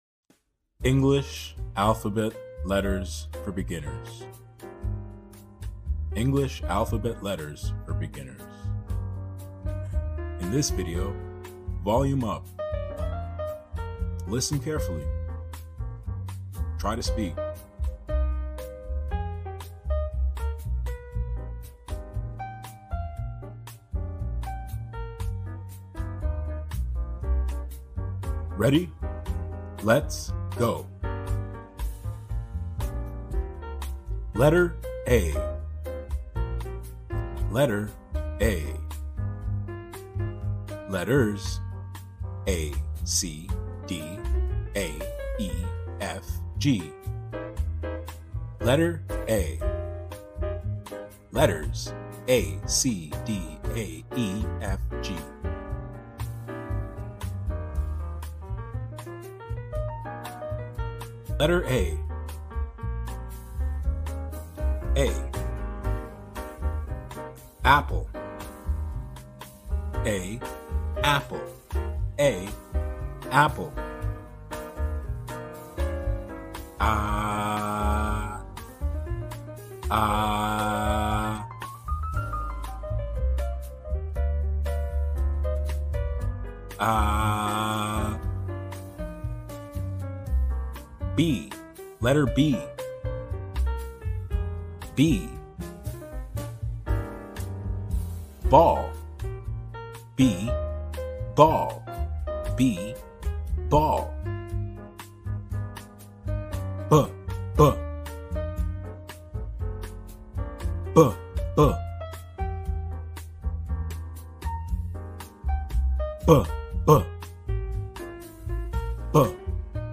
Learn the ABCs with relaxing 432 Hz audio — perfect for beginners!